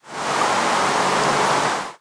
rain3.ogg